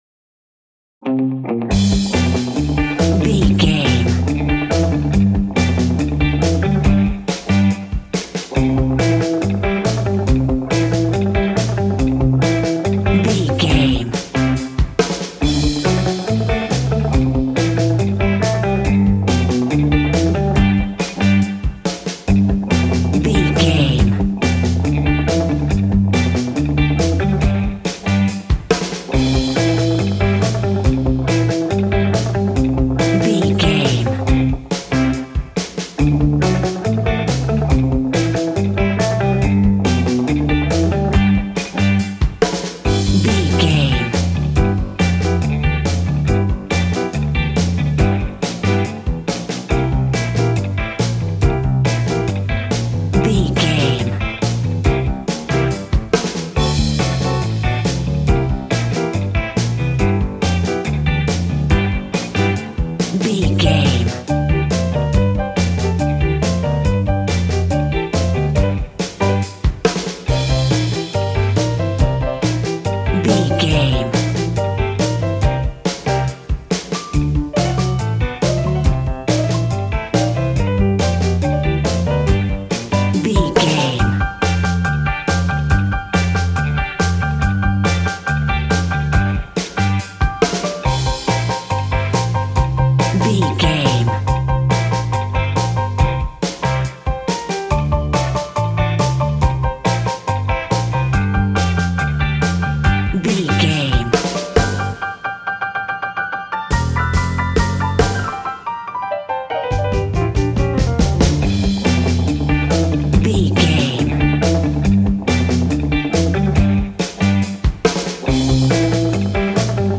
Ionian/Major
cheerful/happy
cool
double bass
drums
piano